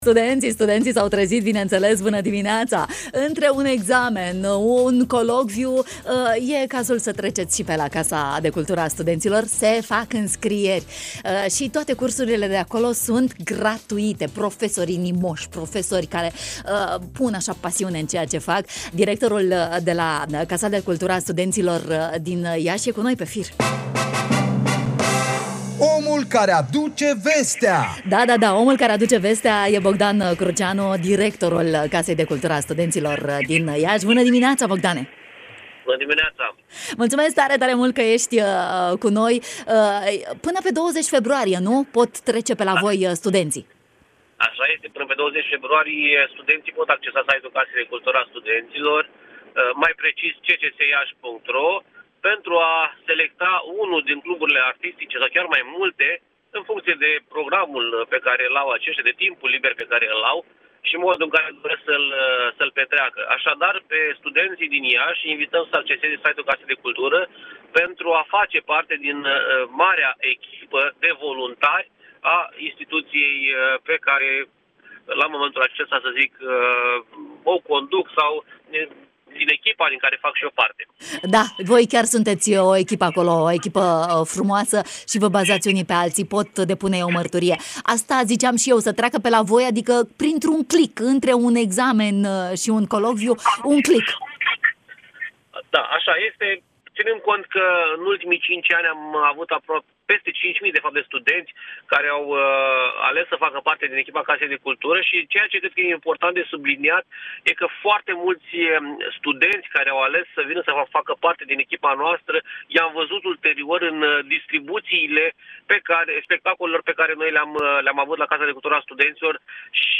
în matinalul de la Radio România Iași: